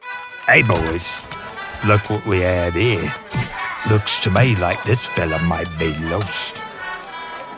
I can do a wide range of characters for your animations, commercials, video games, and more.
Character-Demo-1v2.mp3